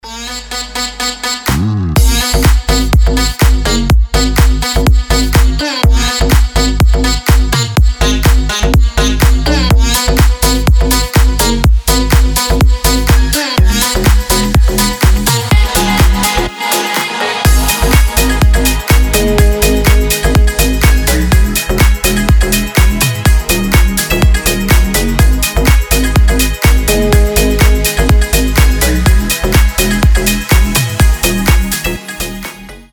энергичный клубняк